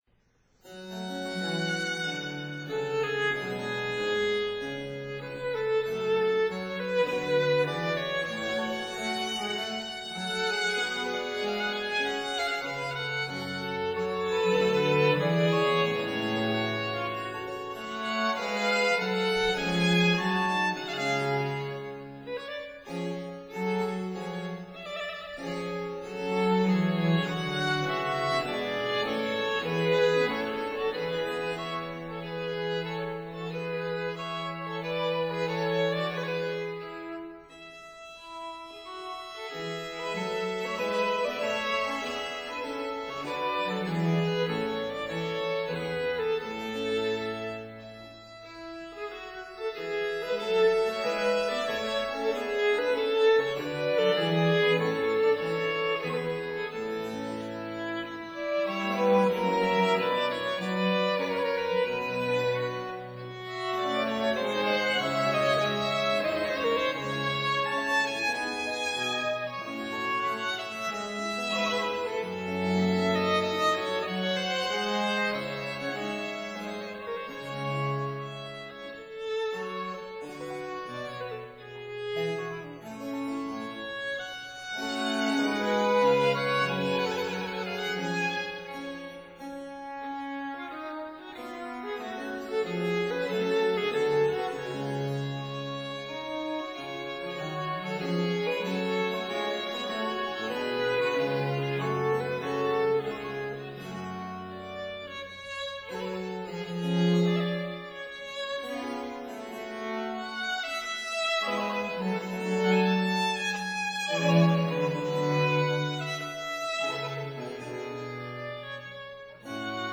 Trio Sonatas for Two Violins & Bassso Continuo, Op. 2
(Period Instruments)